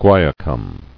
[guai·a·cum]